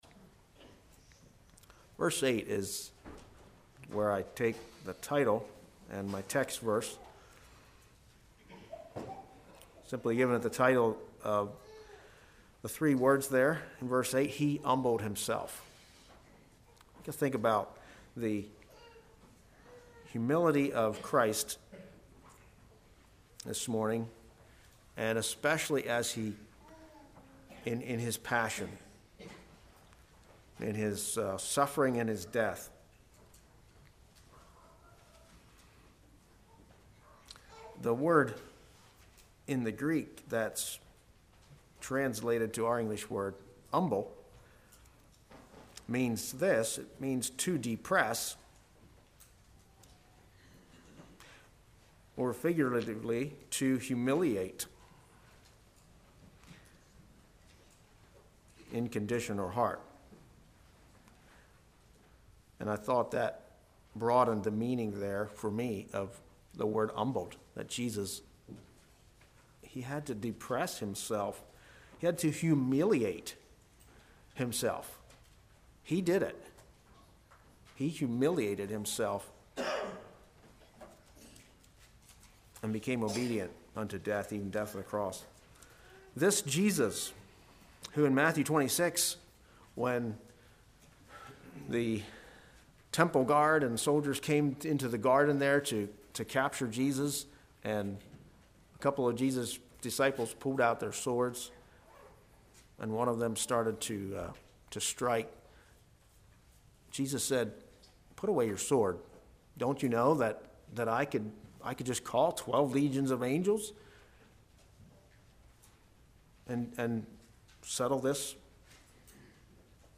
Passion of Christ A Communion Service